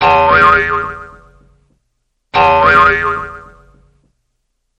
Cartoon - Bounce.mp3